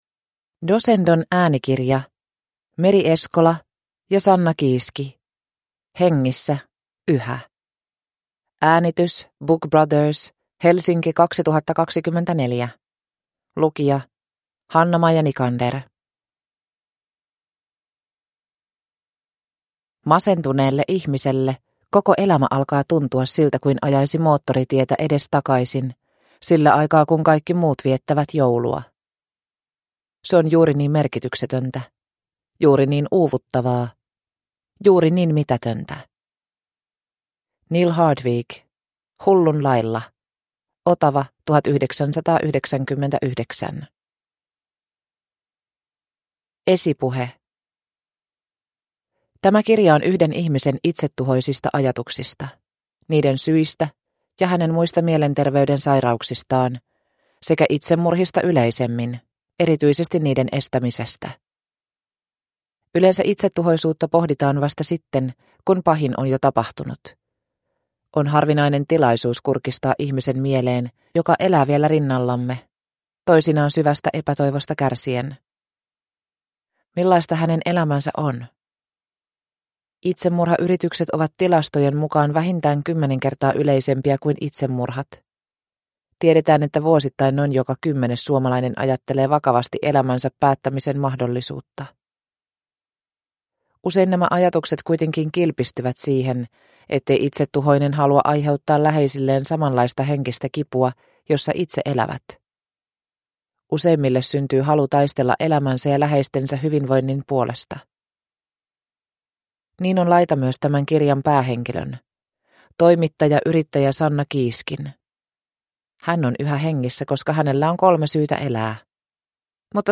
Hengissä, yhä – Ljudbok